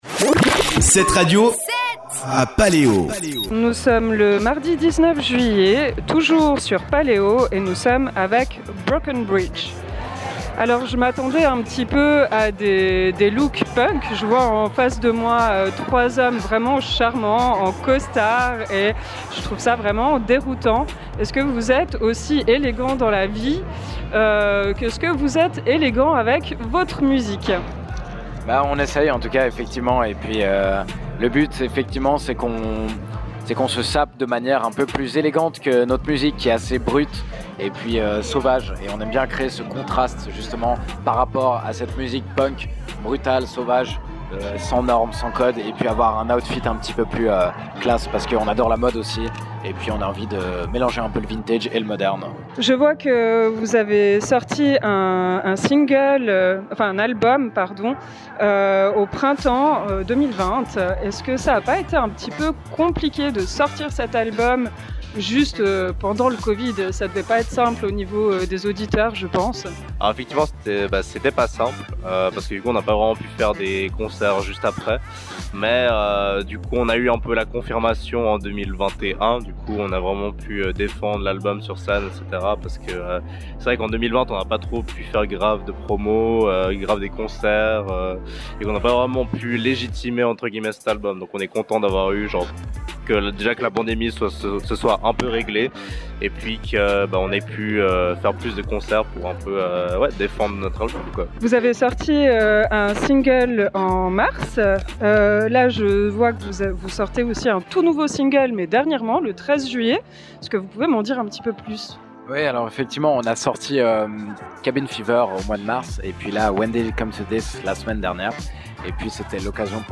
Paléo 2022 – Interview Broken Bridge
Paleo22-BBridge-Itw.mp3